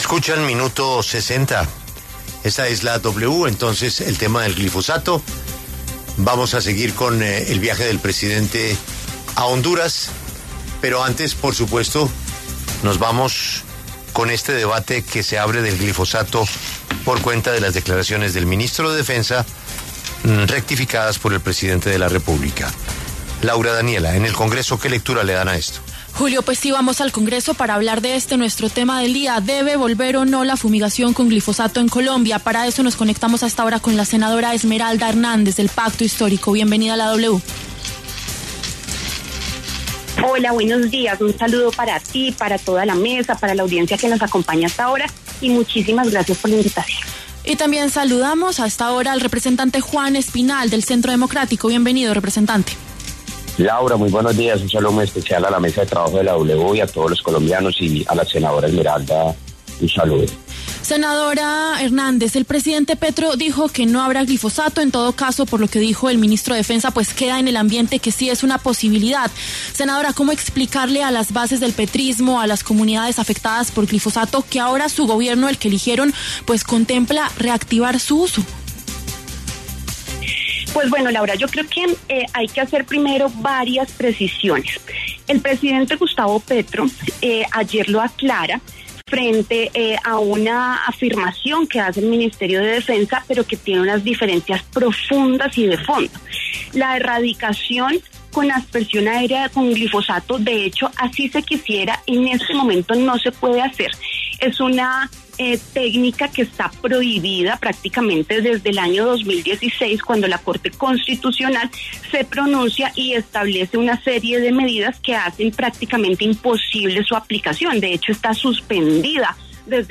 Debate: ¿debe o no volver la fumigación de cultivos ilícitos con glifosato? Hablan congresistas
Esmeralda Hernández, senadora del Pacto Histórico, y Juan Espinal, representante del Centro Democrático, dialogaron en La W sobre la coyuntura.